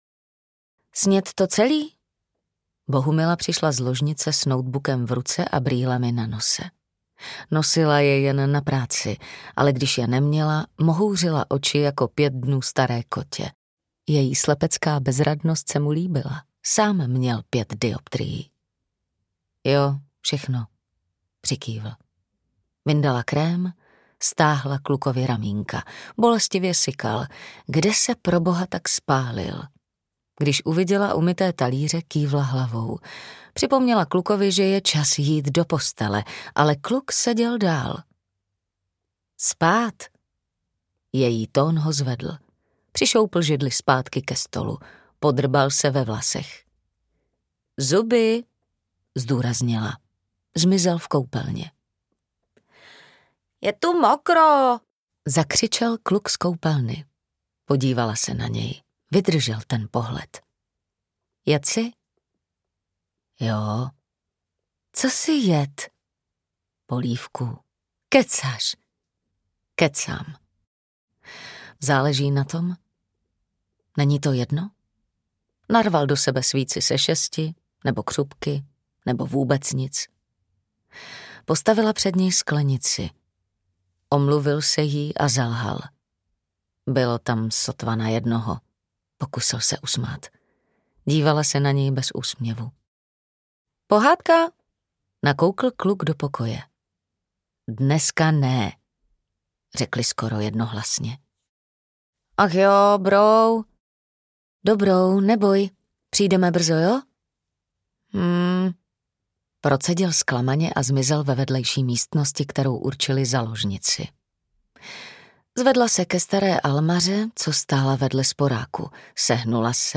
Audio knihaCestou špendlíků nebo jehel
Ukázka z knihy